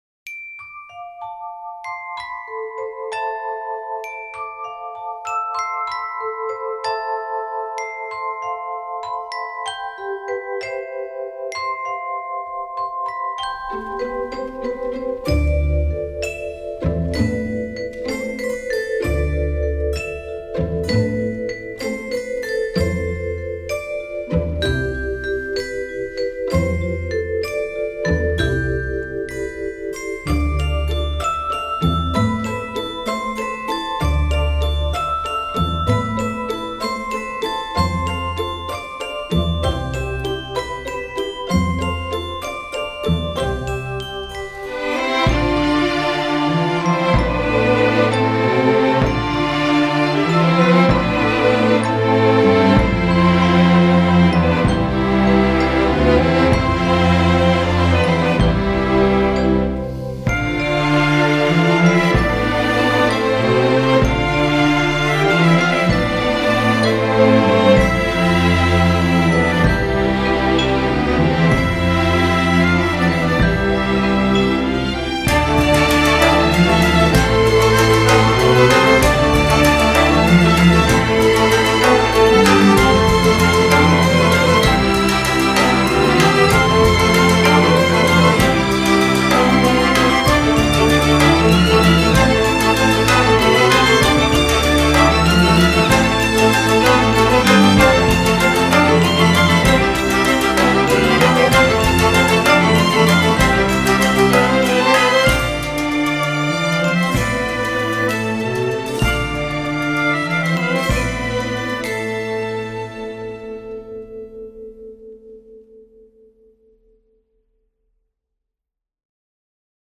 音乐类型:Original Soundtrack
Piano Version
层层叠叠的钢琴、小提琴、手风琴、鼓声、口琴
主旋律带出一次次变奏，旋律就这般悠然荡漾。